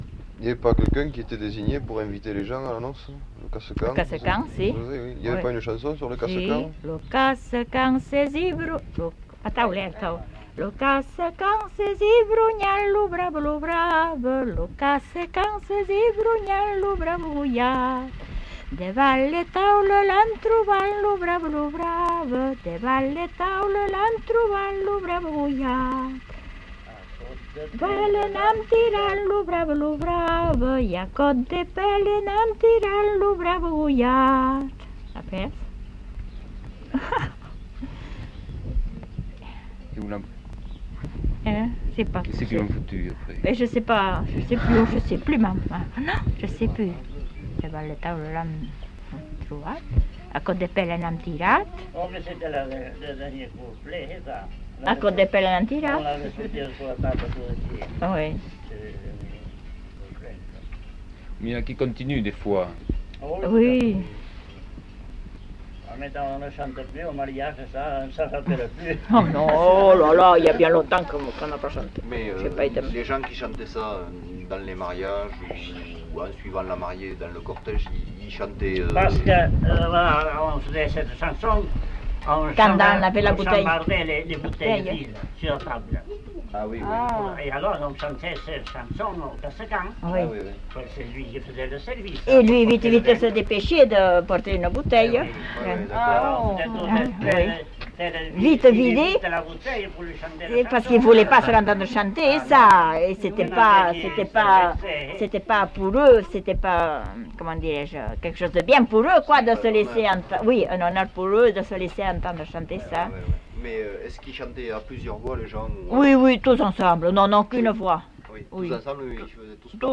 Lieu : Labrit
Genre : chant
Effectif : 1
Type de voix : voix de femme
Production du son : chanté